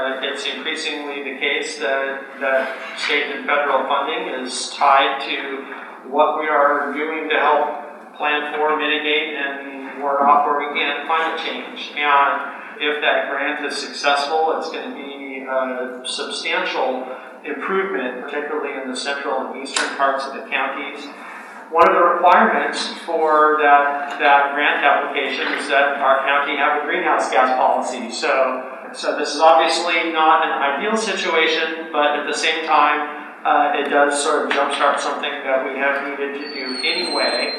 Commissioner Mark Ozias: